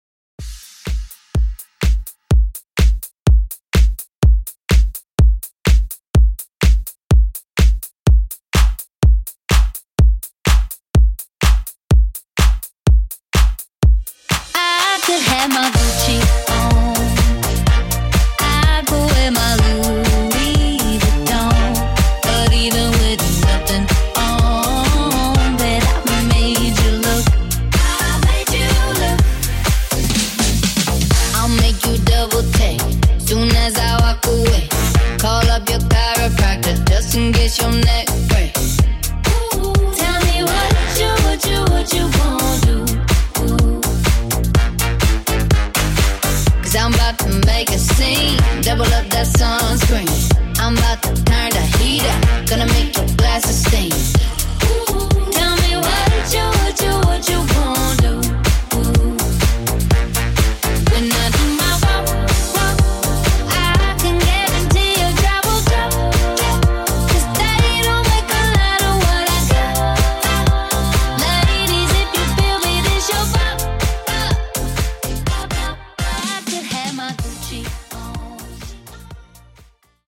Dance Rework)Date Added